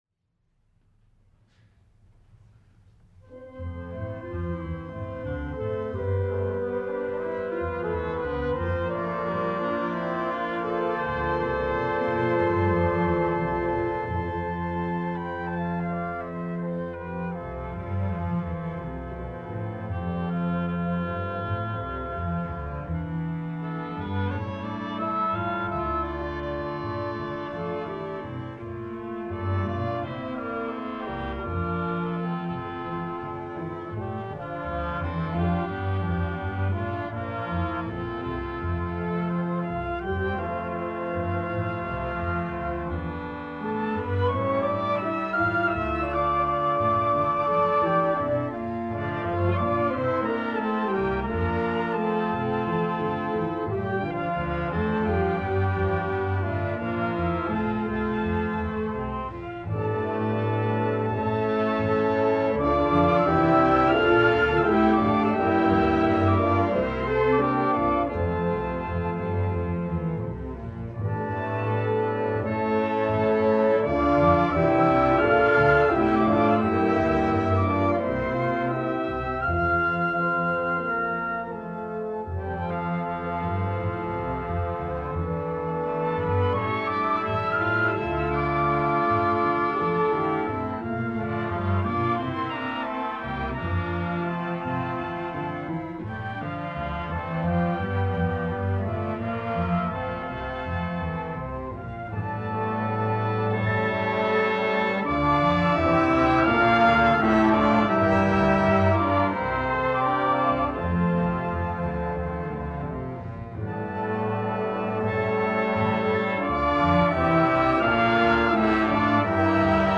Concert Performance March 26, 1974
using a half-track, 10” reel-to-reel Ampex tape recorder.
Armstrong Auditorium, Sunday at 4:00 PM